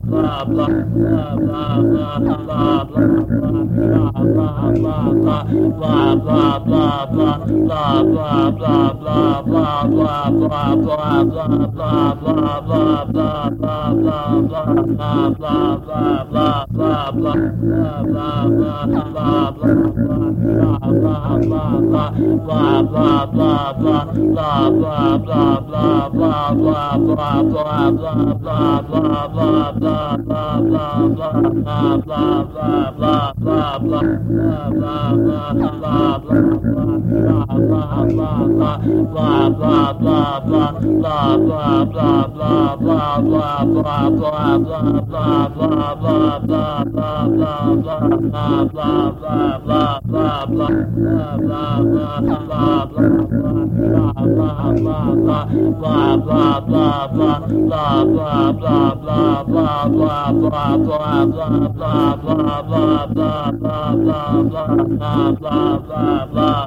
ReeltoReel Tape Loop Recordings of " Blah, Blah, Blah" " blahblahblahcrow
描述：投下正常的声音说出"blah, blah, blah"坚持录制在1/4"磁带上，并进行物理循环（循环它）
Tag: 夸夸其谈-等等 音调下降 带环 语音